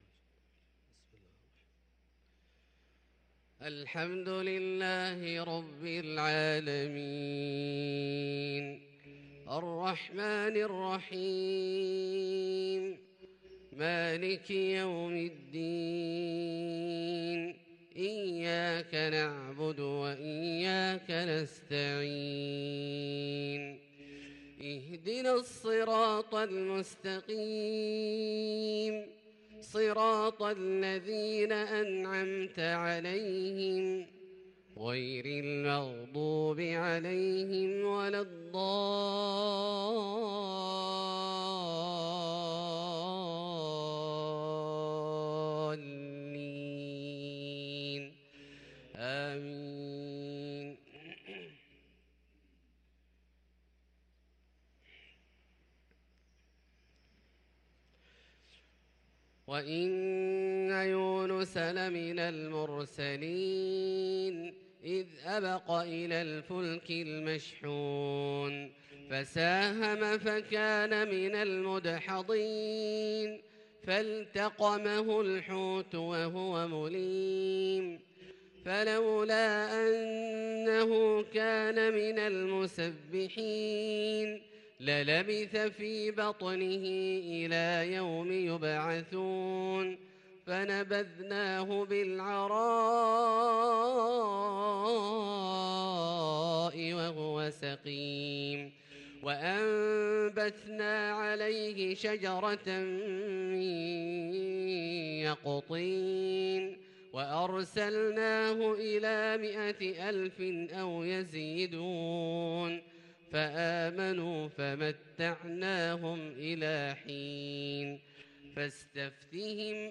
صلاة الفجر للقارئ عبدالله الجهني 29 صفر 1444 هـ